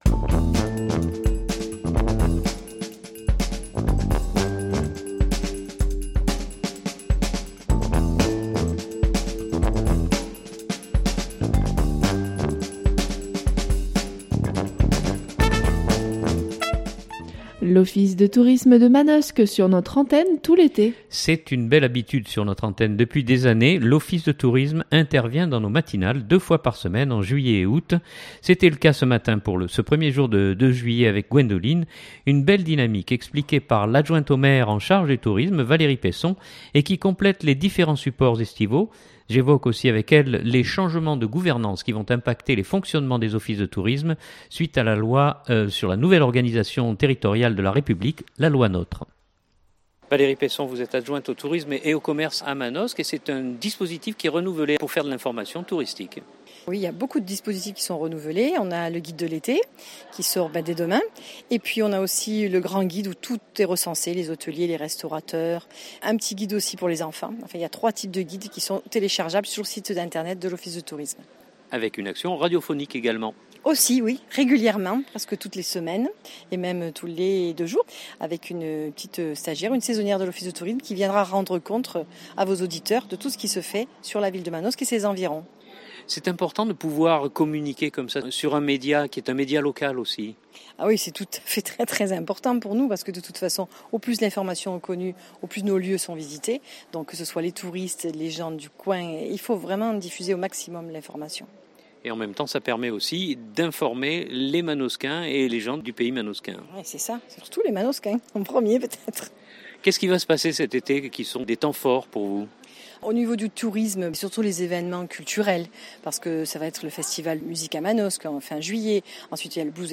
C’est une belle habitude sur notre antenne depuis des années, l’office de tourisme intervient dans nos matinales deux fois par semaine en juillet et août.